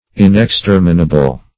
Inexterminable \In`ex*ter"mi*na*ble\, a. [L. inexterminabilis.